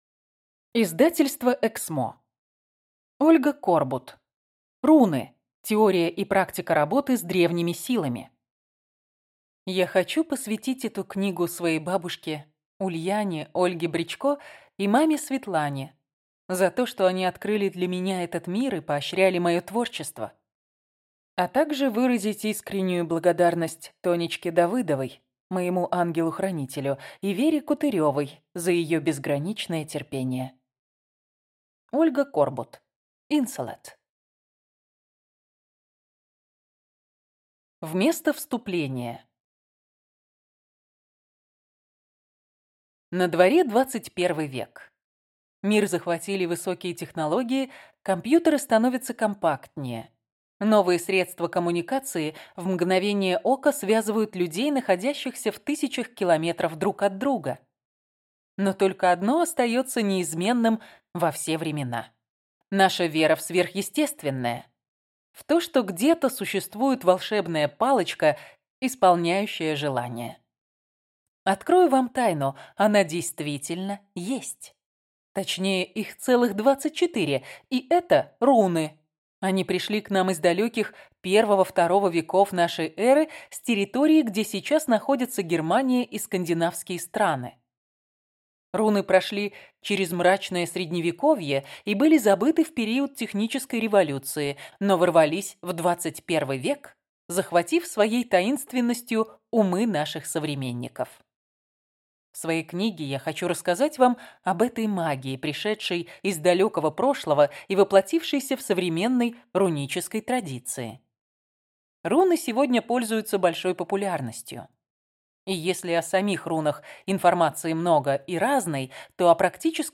Аудиокнига Руны. Теория и практика работы с древними силами | Библиотека аудиокниг